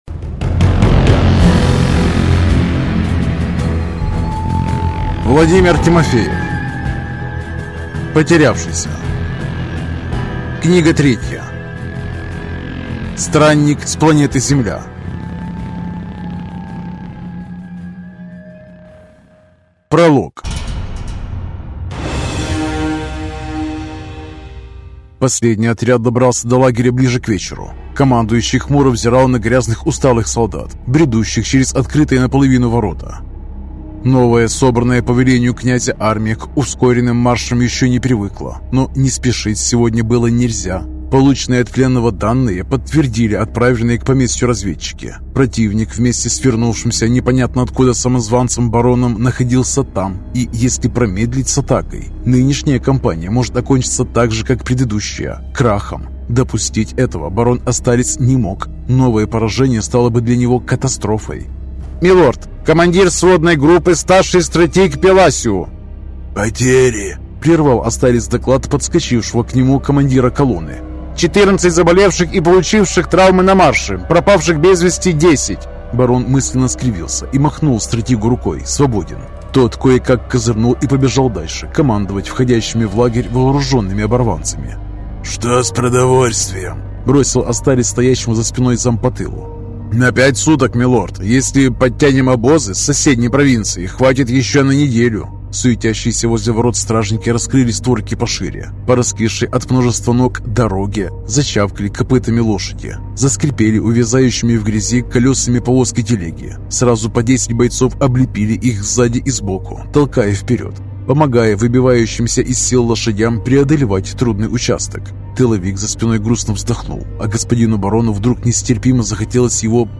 Аудиокнига Странник с планеты Земля | Библиотека аудиокниг